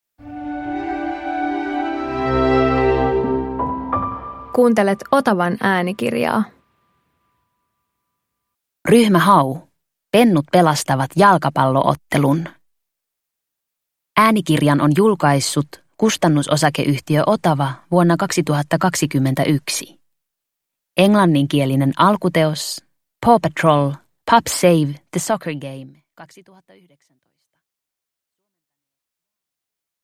Ryhmä Hau - Pennut pelastavat jalkapallo-ottelun – Ljudbok – Laddas ner